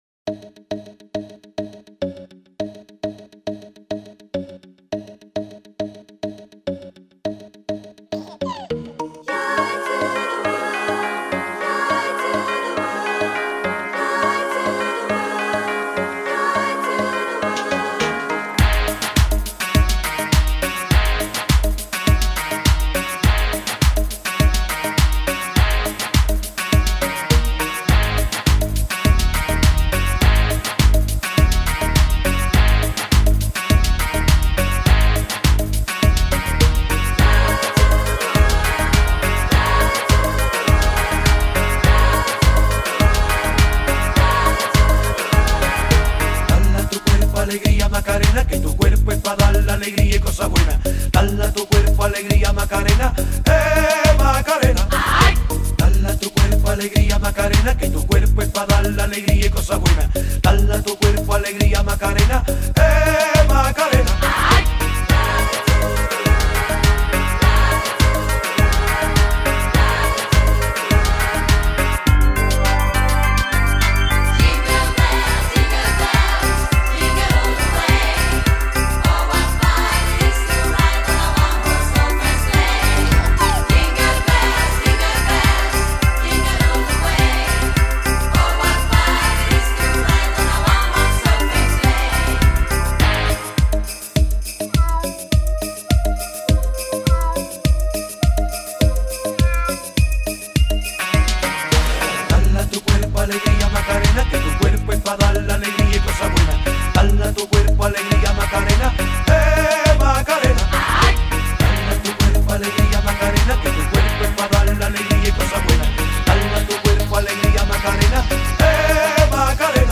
浓缩100首DJ精选 锐舞热碟大引爆
绝对现场 绝对激昂 最酷的锐舞音乐